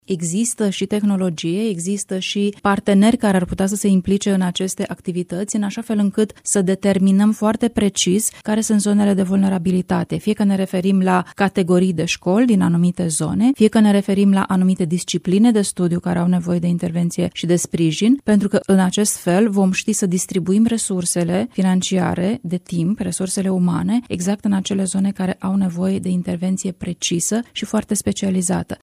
Reforma educației ar trebui să înceapă cu introducerea unui sistem extins de evaluări externe, a declarat în emisiunea, Dezbaterea Zilei, Luciana Antoci, consilier de stat în cancelaria prim-ministrului.